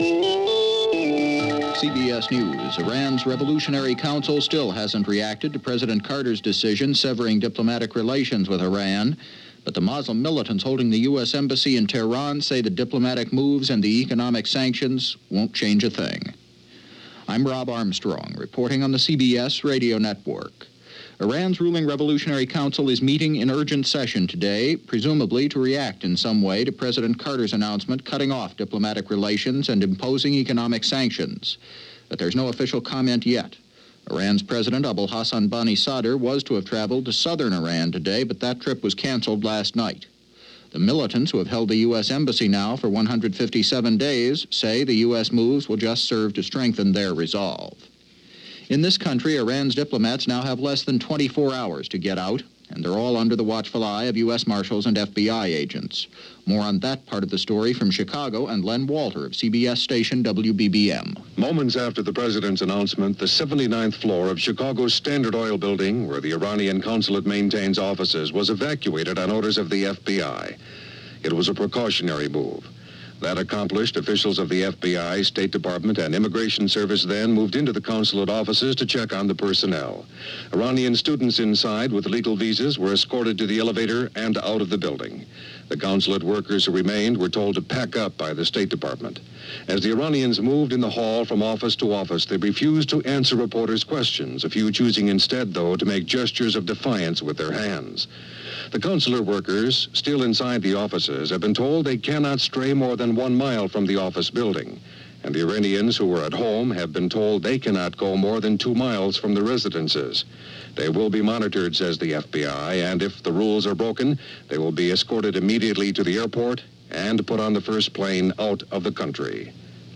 April 8, 1980 – CBS World News Roundup + News On The Hour + Newsbreak